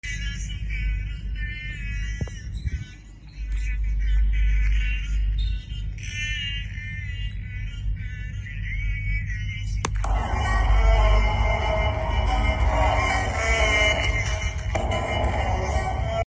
MiniSpeaker on the phone! 8D sound effects free download